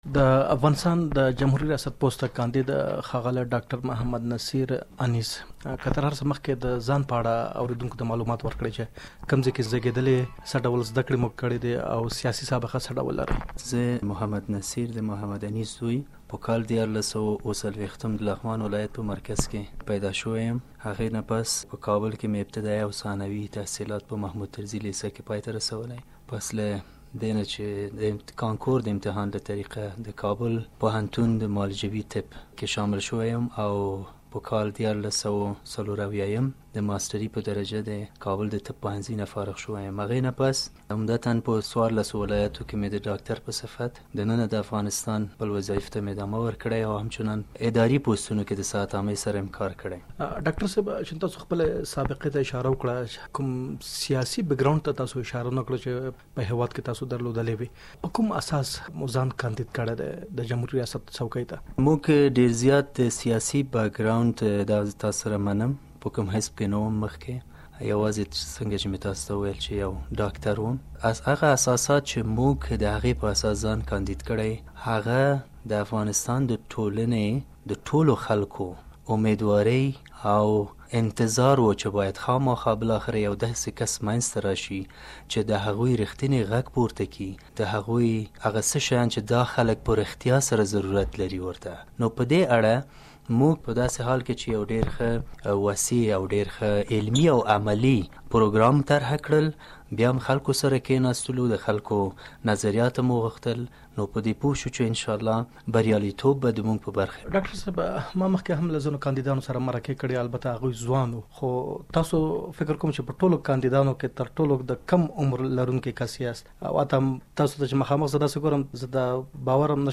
ځانګړې مرکه